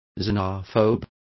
Complete with pronunciation of the translation of xenophobe.